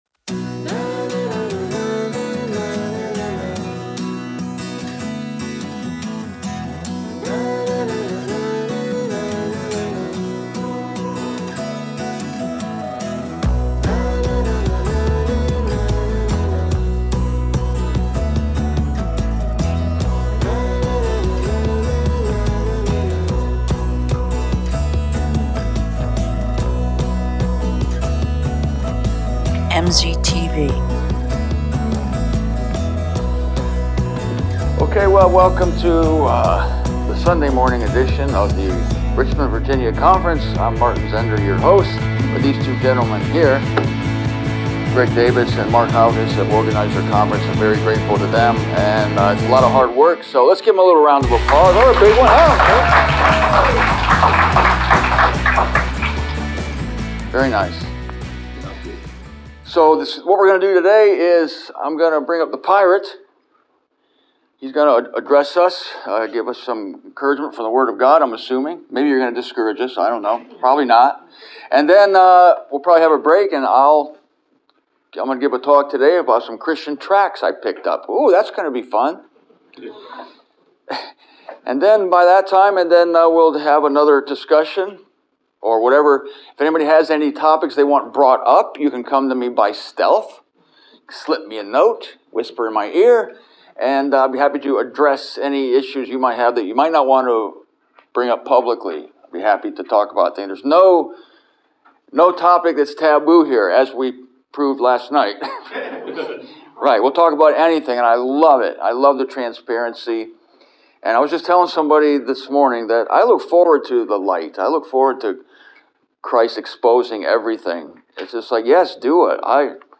Richmond Conference